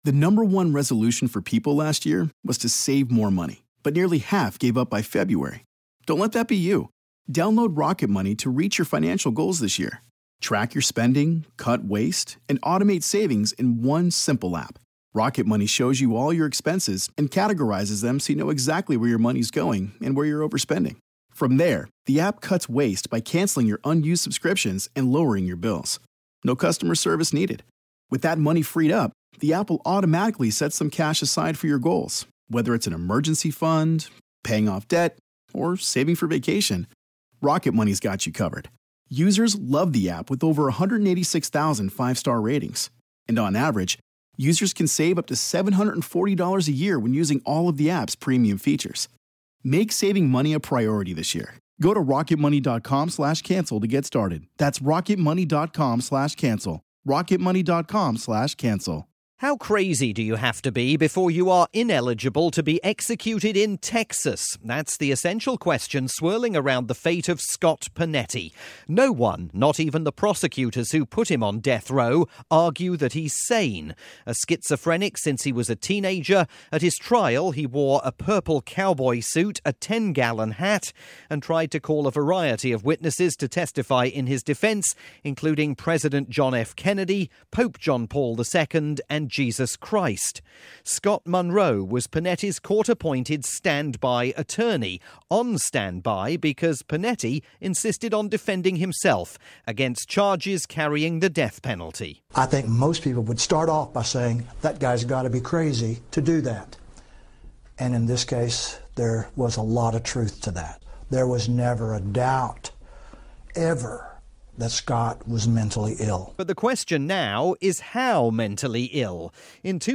This report aired on Radio New Zealand's nightly "Checkpoint" program.